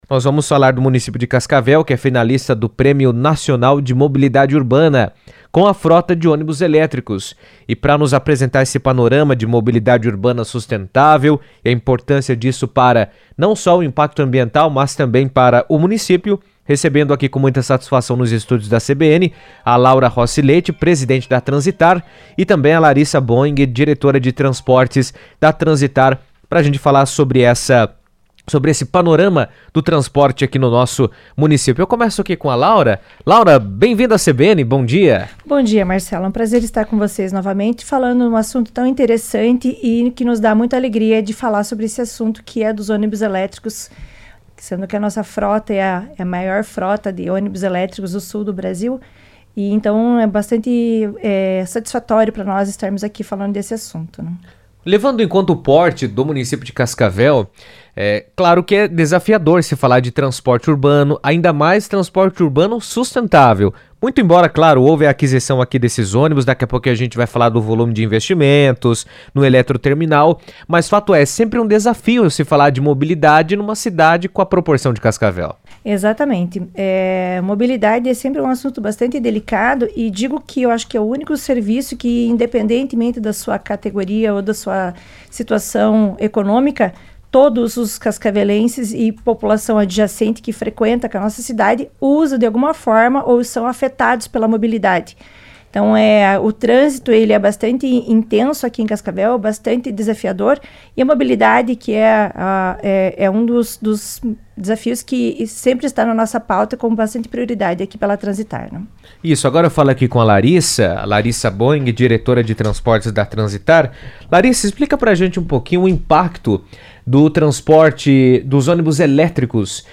Cascavel se destaca na área de mobilidade urbana ao concorrer a um prêmio nacional com sua frota de ônibus elétricos, iniciativa que reforça o compromisso da cidade com transporte sustentável e inovação tecnológica. Em entrevista à CBN